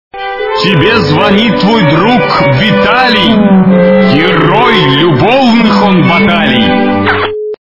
При прослушивании Звонок от Виталия - Тебе звонит твой друг Виталий, герой любовных он баталий качество понижено и присутствуют гудки.